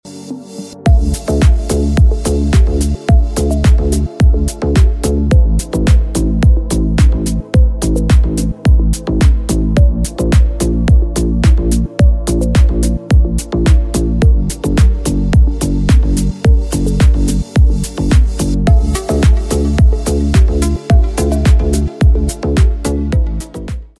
1/2'' 2'' PVC Double Exit Pipe sound effects free download
1/2''-2'' PVC Double Exit Pipe Extrusion Line, PVC Pipe Extrusion Machinery, PVC Pipe Extruder. It boosts productivity by producing two pipes simultaneously, cutting unit production costs.